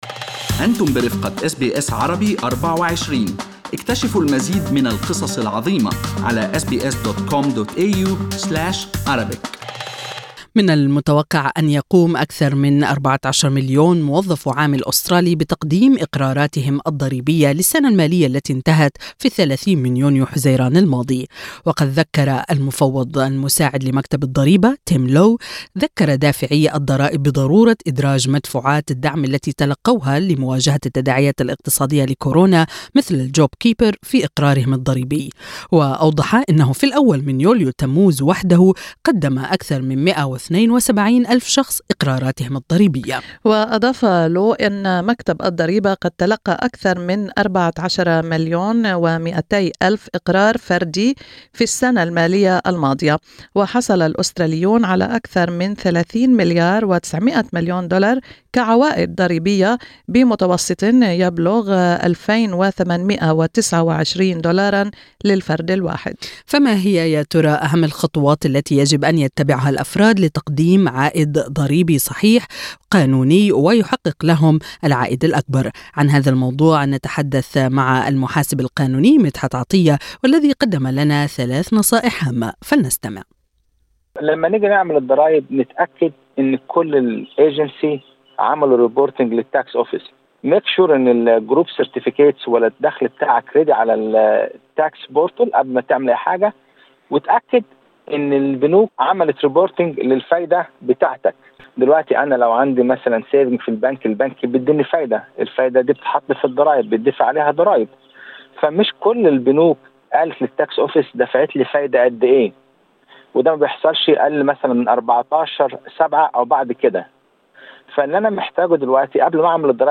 اللقاء الكامل